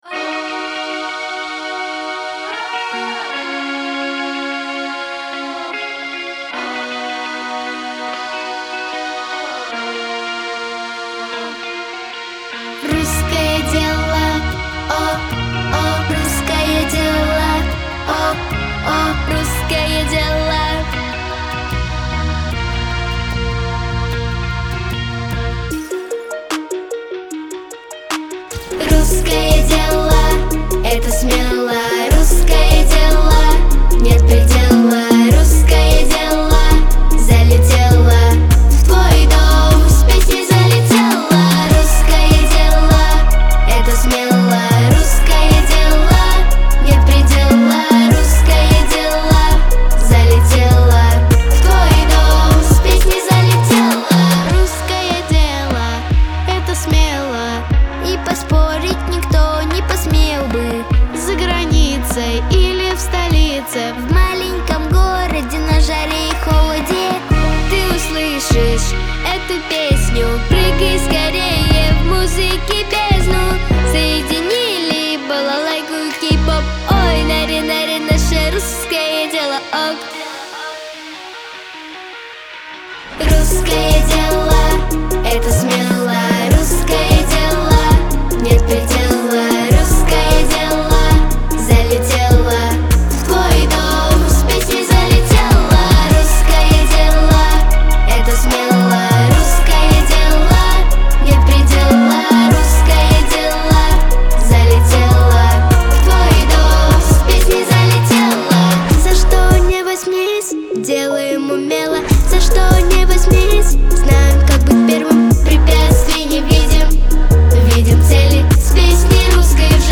Это энергичный гимн русской культуре и духу.